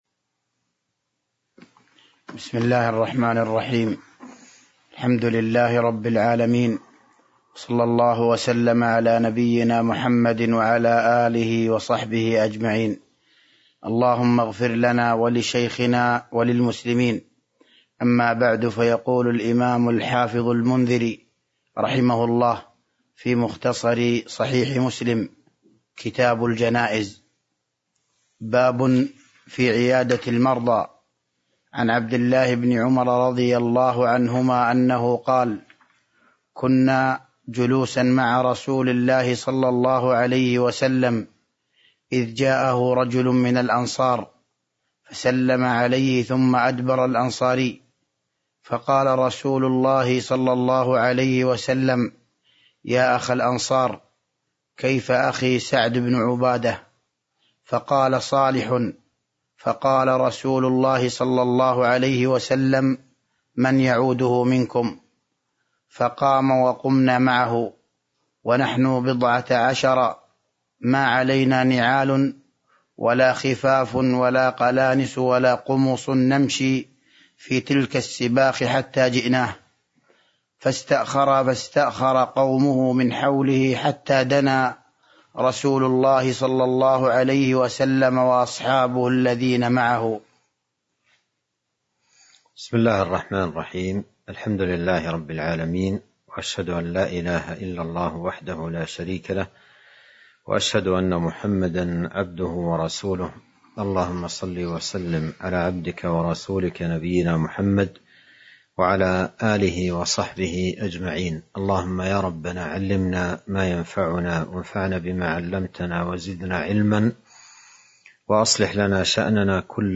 تاريخ النشر ٢٦ جمادى الآخرة ١٤٤٢ هـ المكان: المسجد النبوي الشيخ: فضيلة الشيخ عبد الرزاق بن عبد المحسن البدر فضيلة الشيخ عبد الرزاق بن عبد المحسن البدر باب في عيادة المرضى (01)